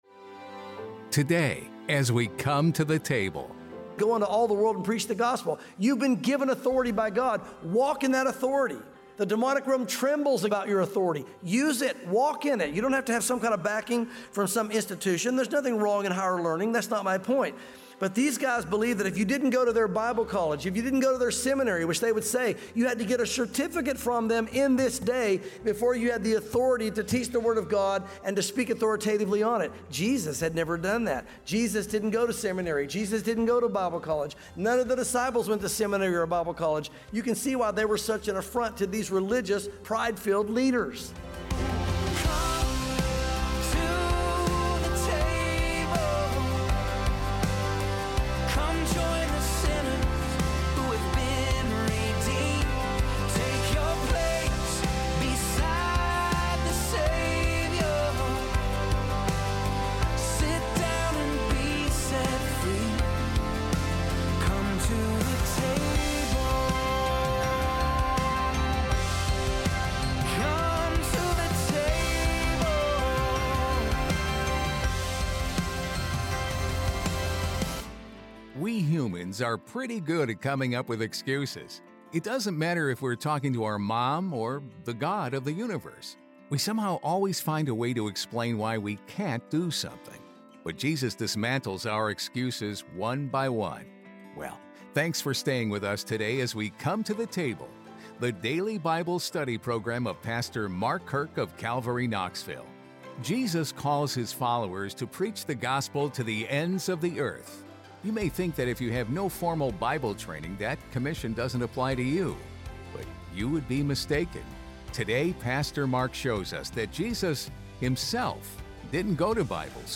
sermons
Welcome to Calvary Chapel Knoxville!